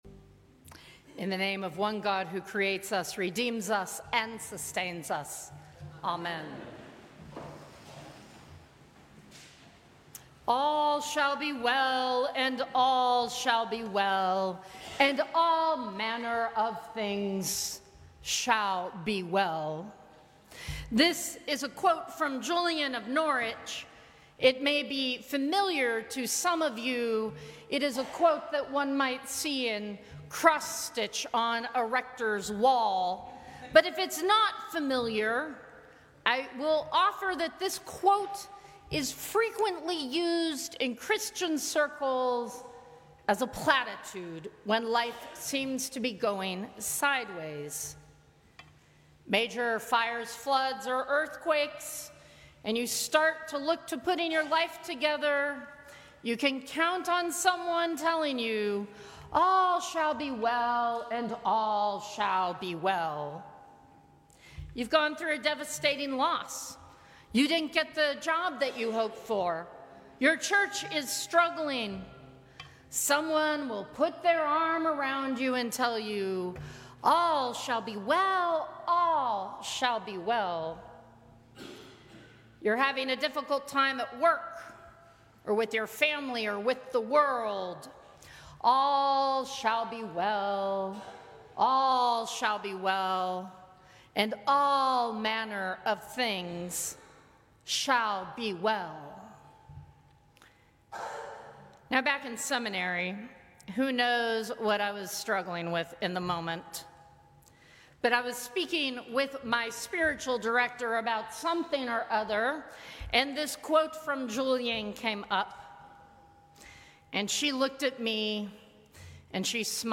Sermons from St. Cross Episcopal Church Sixth Sunday after the Epiphany Feb 16 2025 | 00:12:46 1x 00:00 / 00:12:46 Subscribe Share Apple Podcasts Spotify Overcast RSS Feed Share Link Embed